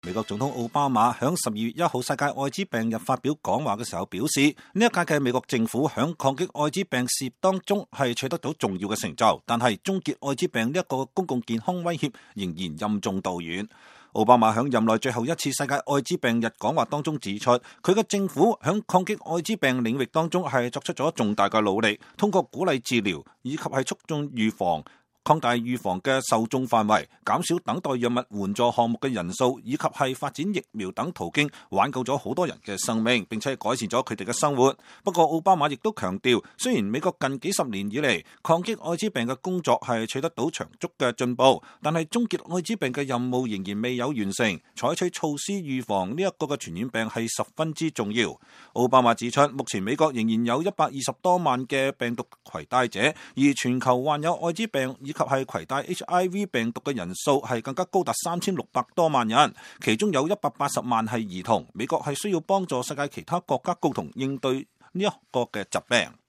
美國總統奧巴馬在12月1日“世界愛滋病日”發表講話說，本屆美國政府在抗擊愛滋病事業中取得了重要成就，但是終結愛滋病這一公共健康威脅仍然任重道遠。